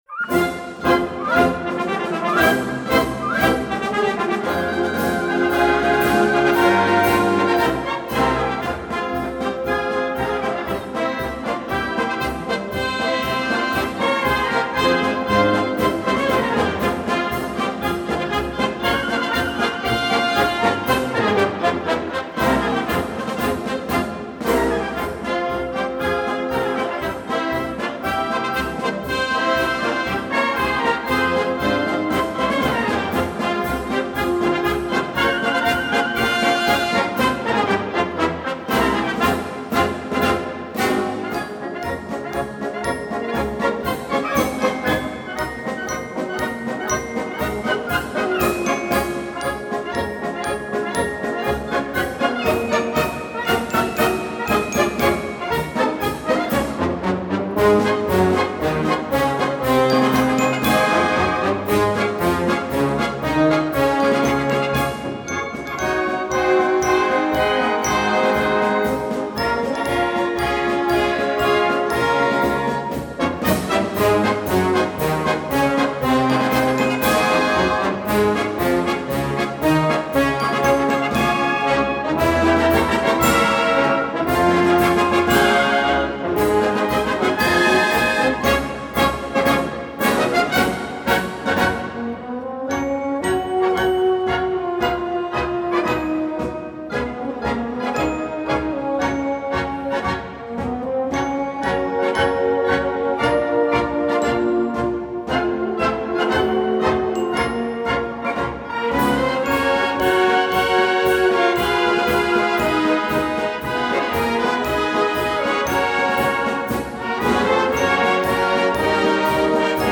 Композиція на теми старовинних військових маршів